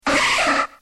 Cri de Nidorina dans Pokémon X et Y.